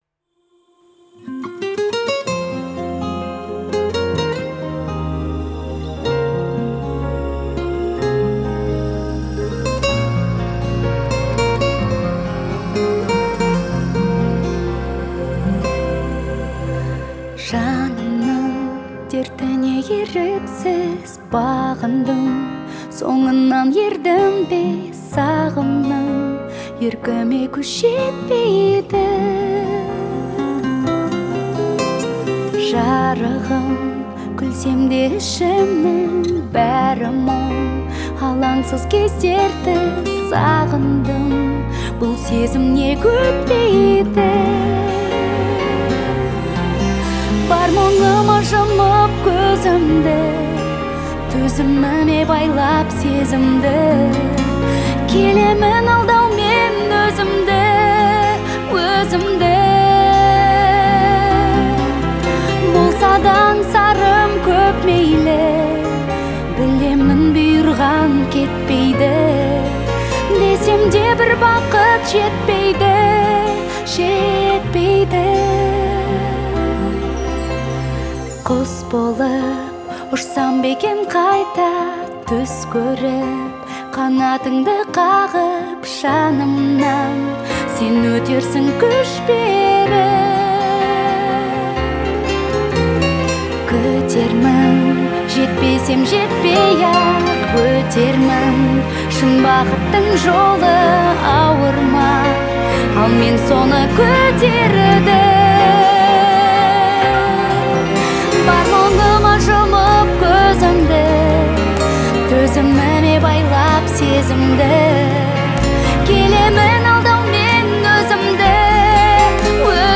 это душевная и мелодичная песня в жанре поп